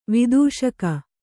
♪ vidūṣaka